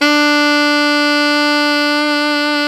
Index of /90_sSampleCDs/Roland LCDP07 Super Sax/SAX_Alto Tube/SAX_Alto ff Tube
SAX ALTOFF08.wav